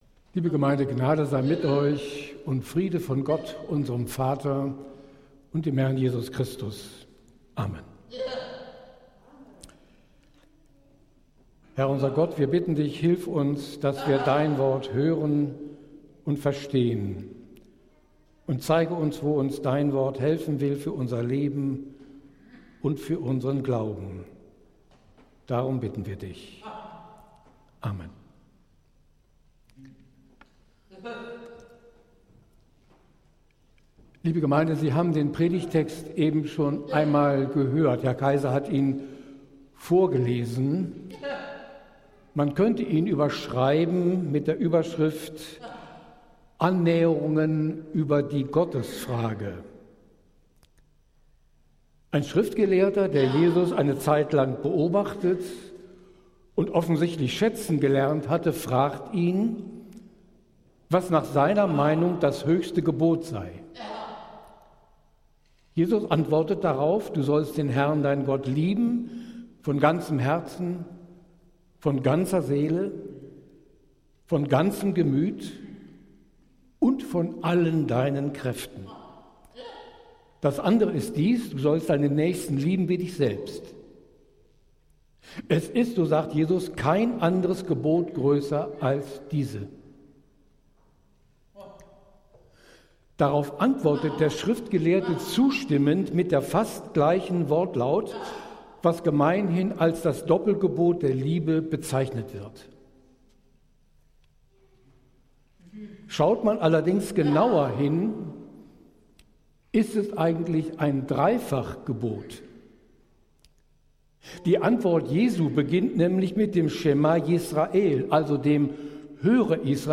Predigt des Gottesdienstes aus der Zionskirche am Sonntag, den 24. August 2025
Wir haben uns daher in Absprache mit der Zionskirche entschlossen, die Predigten zum Nachhören anzubieten.